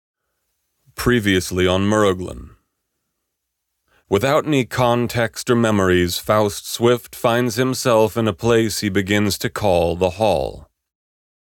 How do I remove that background noise..
Attached a wav file of an intro to a book I’m doing. I can’t seem to figure out how to remove the background noise from the recording.
The magic-ingredient you’re missing to remove the background noise is Mosquito Killer on 2 to get rid of the faint constant whine …